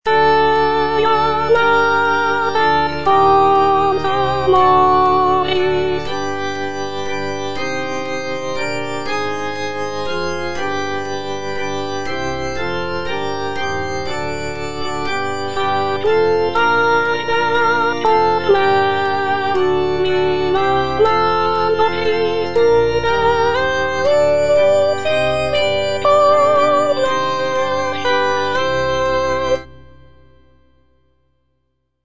G.P. DA PALESTRINA - STABAT MATER Eja Mater, fons amoris (soprano II) (Voice with metronome) Ads stop: auto-stop
sacred choral work